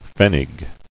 [pfen·nig]